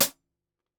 DB SWUNG_HH.wav